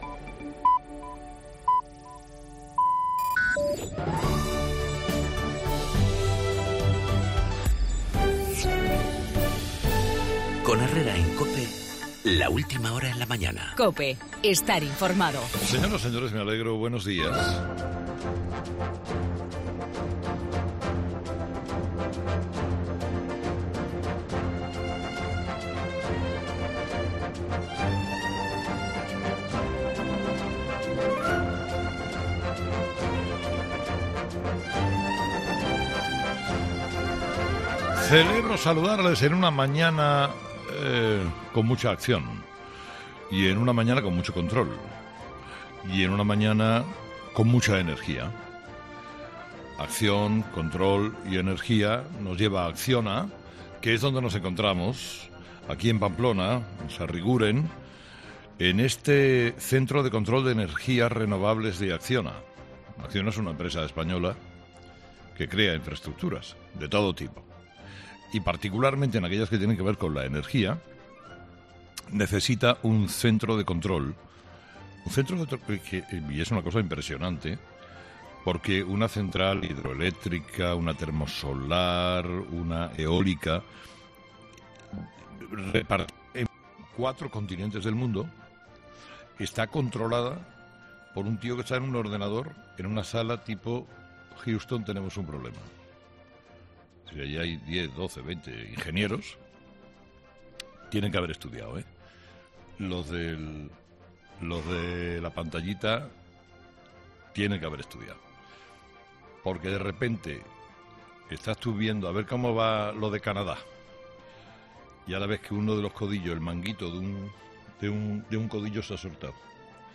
Monólogo de las 8 de Herrera
La moción de censura de Podemos y la salida de la cárcel de la terrorista Idoia López Riaño, en el monólogo de Carlos Herrera a las 8 de la mañana desde Acciona, Navarra.